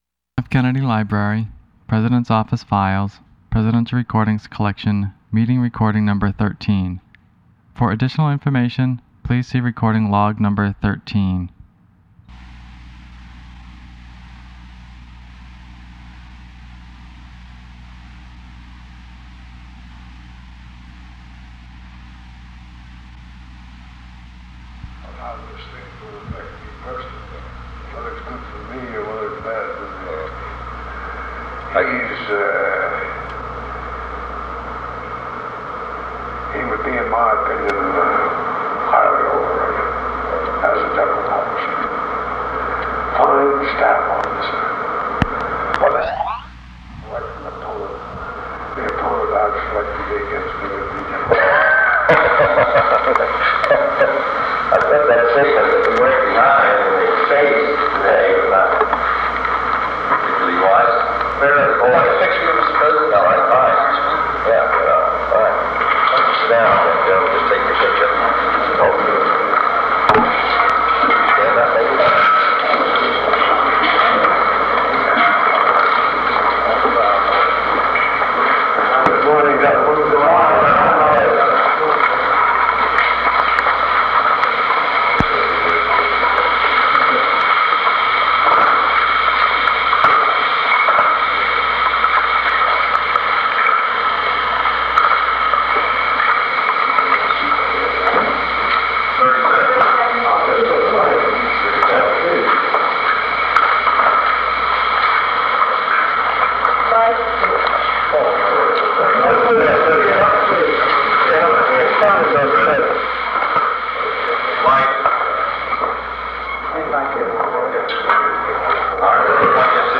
Secret White House Tapes | John F. Kennedy Presidency Meeting with Douglas MacArthur (cont.)